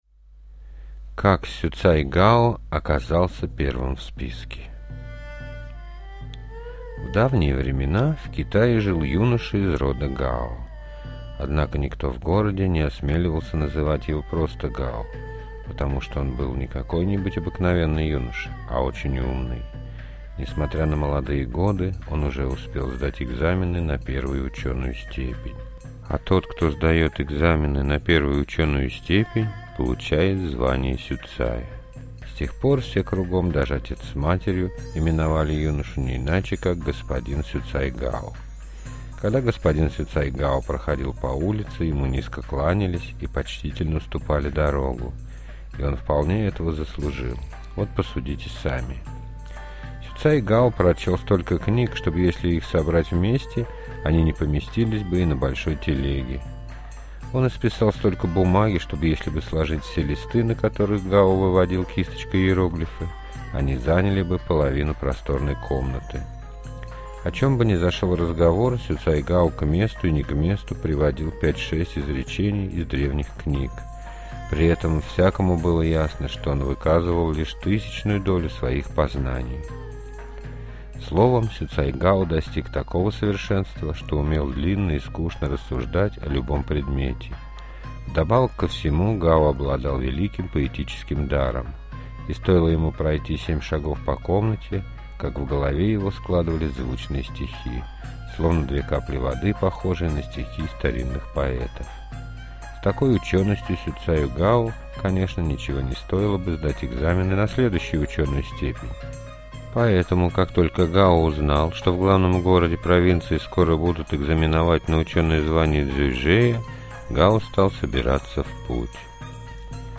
Как Сюцай Гао оказался первым в списке – китайская аудиосказка